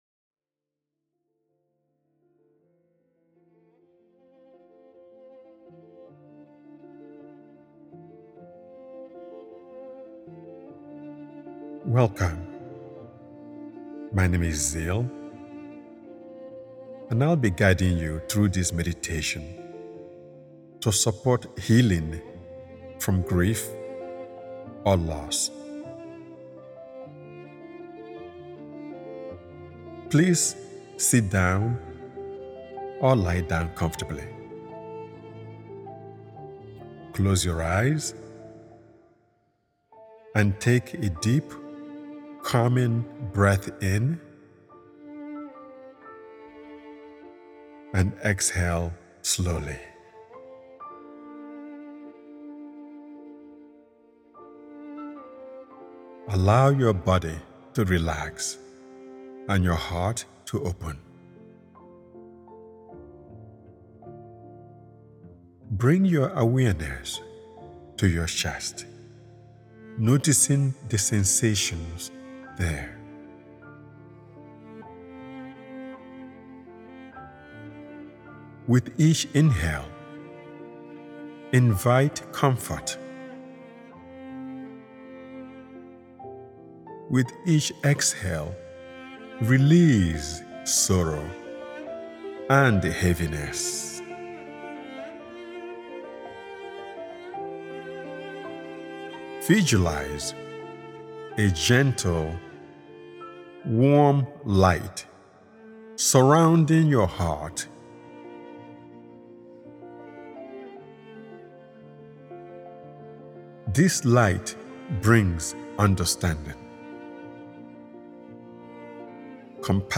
Healing Grief: A Meditation for Comfort & Renewal offers a safe, compassionate space to soften the weight of sorrow and reconnect with inner peace.